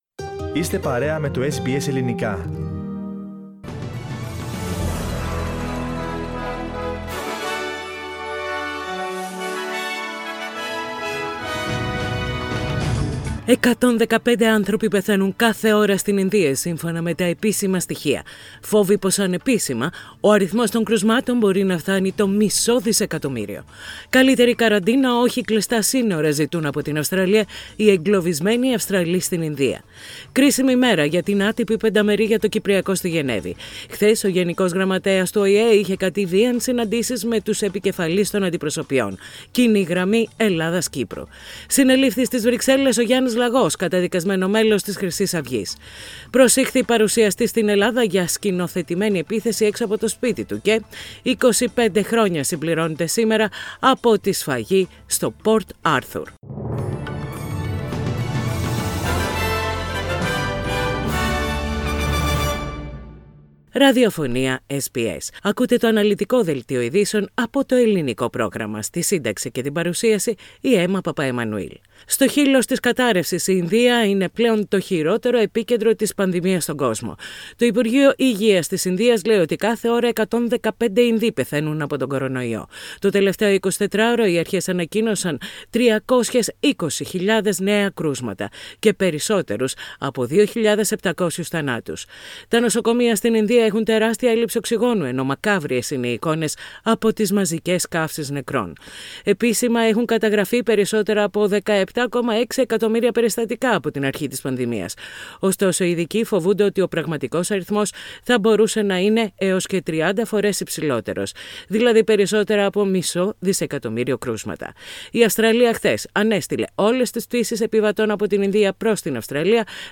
Ειδήσεις στα Ελληνικά - Τετάρτη 28.4.21